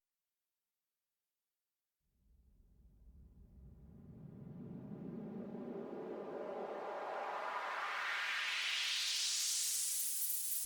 rise_woosh